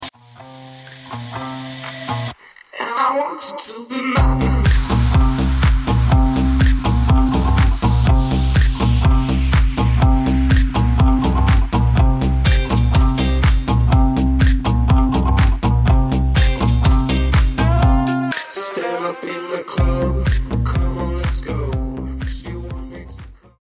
Извиняюсь за качество записи.